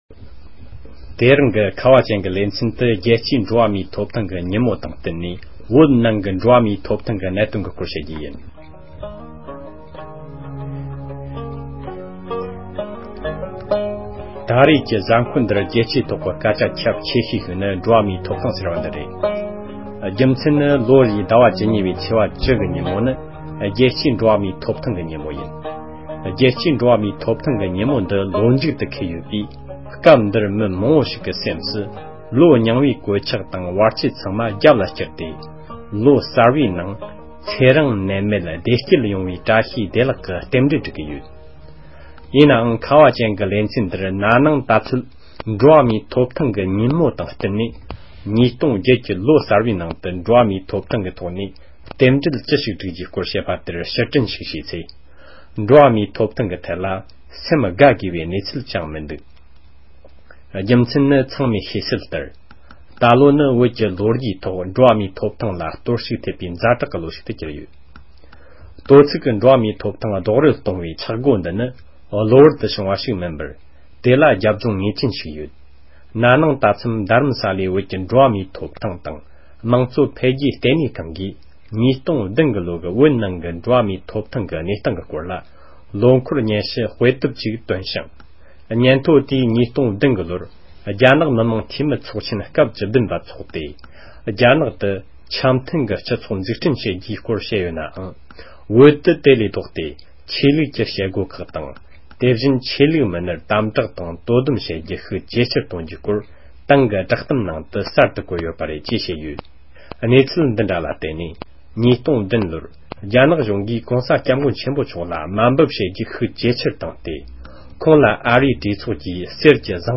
དཔྱད་གཏམ